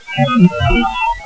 I recorded dozens of spirit voices in the short time I was there.
On this page, you will find the best of those recorded voices from the Marceline, MO. cemetery.
We have absolutly no clue why this ghost / spirit lady it saying this.
However...its pretty clear she is saying....